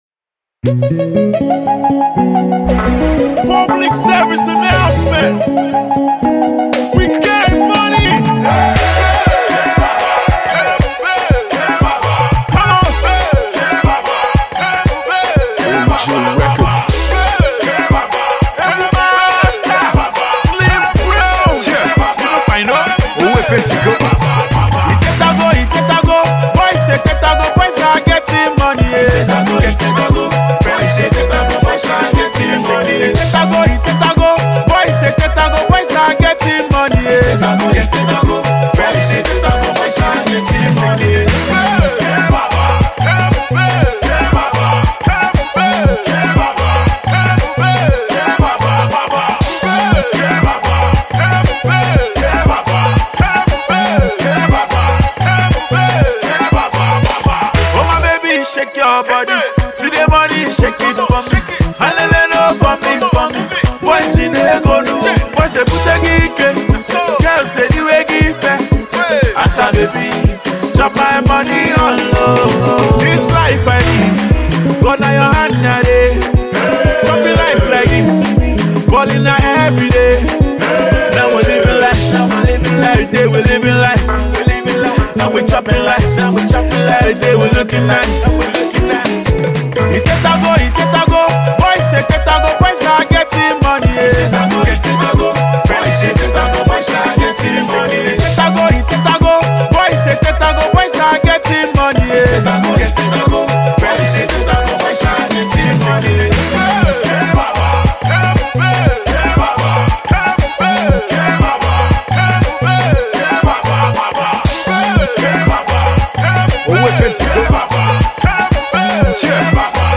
dance floor banger